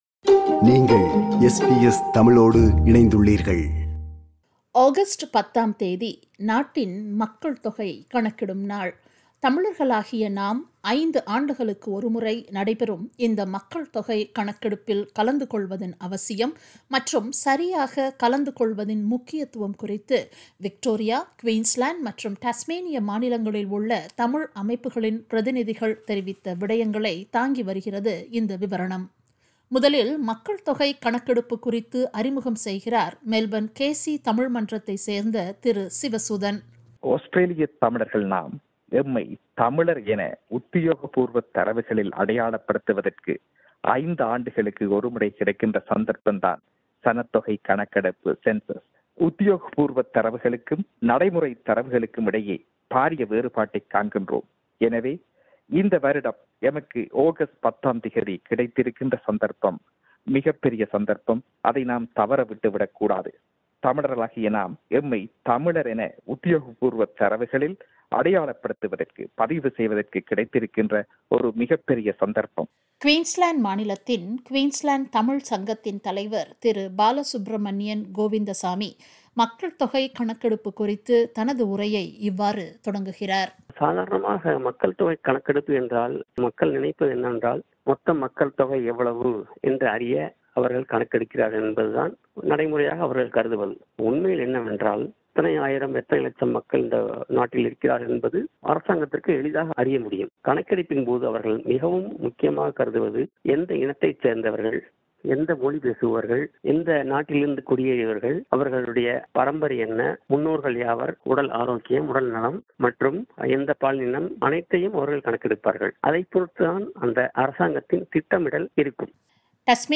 விவரணம்